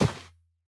Minecraft Version Minecraft Version snapshot Latest Release | Latest Snapshot snapshot / assets / minecraft / sounds / mob / camel / step_sand6.ogg Compare With Compare With Latest Release | Latest Snapshot
step_sand6.ogg